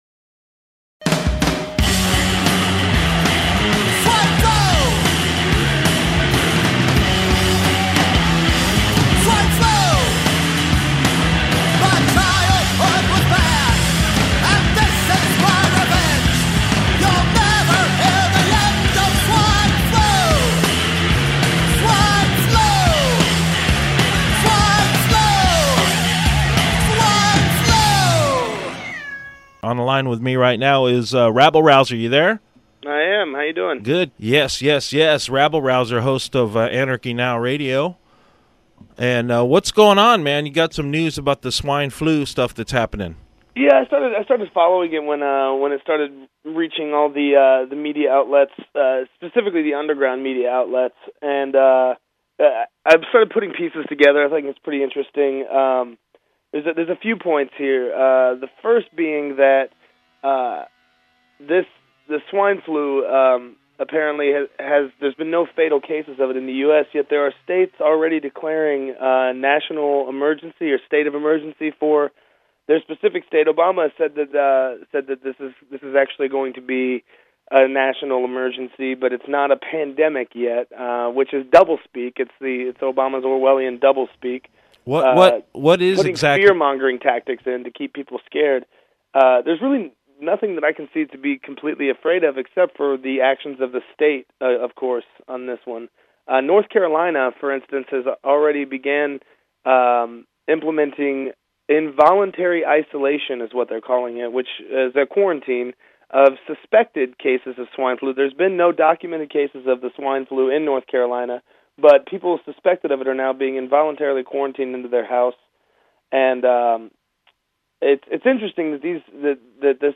Interviewed on Free Radio Santa Cruz 101fm 04-28-09